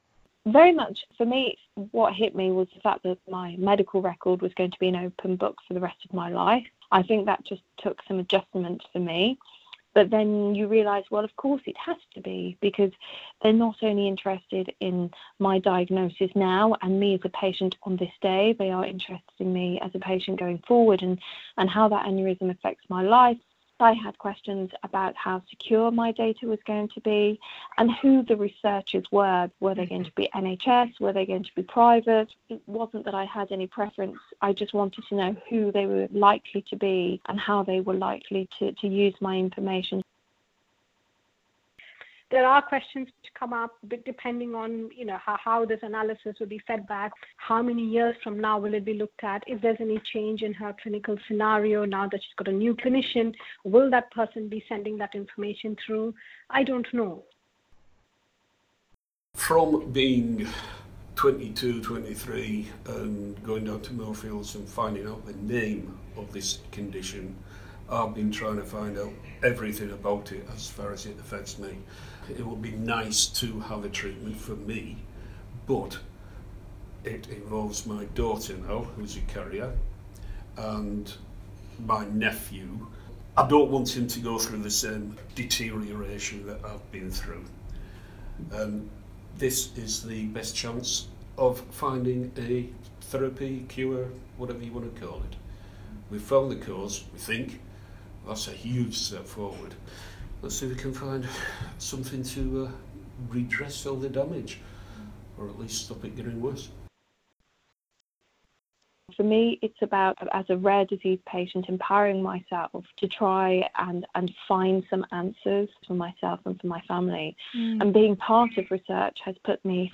Interview-Clip-v2.wav